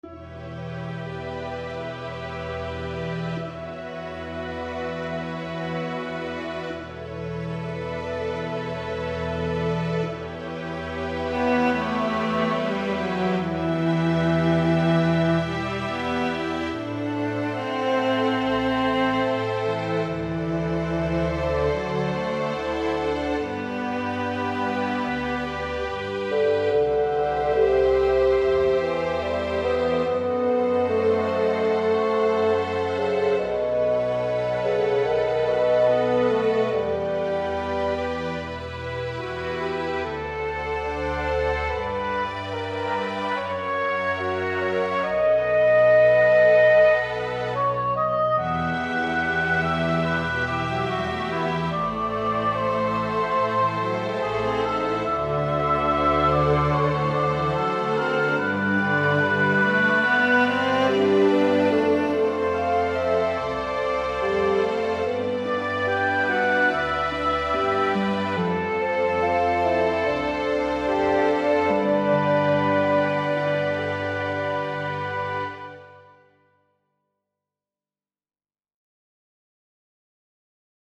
Miroslav Philharmonik (IK Multimedia) has quite a few flavors of Flugelhorn VSTi instruments, so I replaced the French Horn in the two versions that you like with Flugelhorn . . .